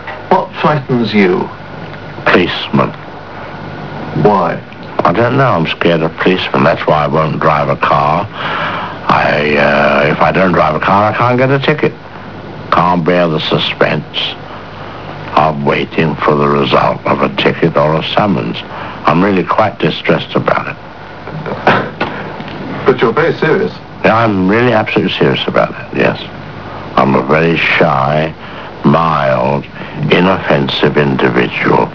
Des extraits d'interviews
Comme vous pourrez l'entendre, Hitchcock avait la particularité d'avoir un débit de parole extrêmement lent, à la limite du crispant pour un anglophone mais bien agréable à écouter pour les malheureux francophones que nous sommes...